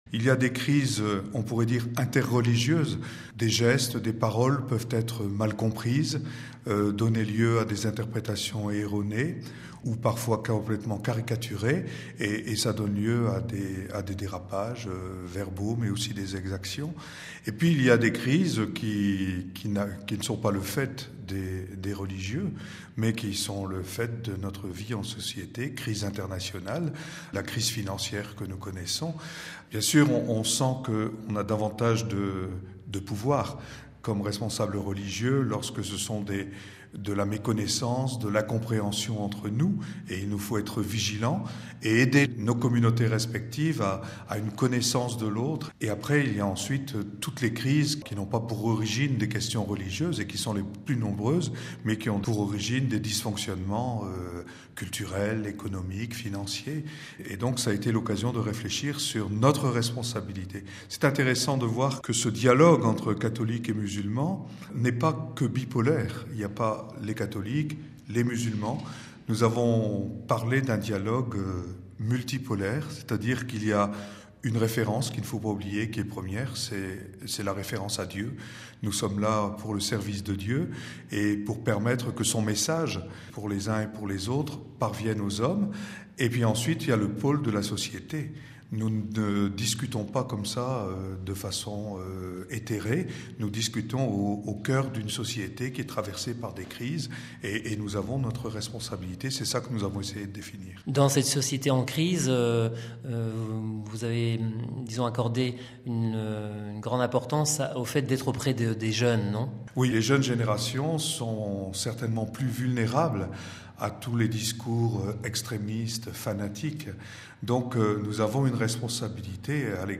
Monseigneur Brunin, évêque d’Ajaccio participait à cette rencontre RealAudio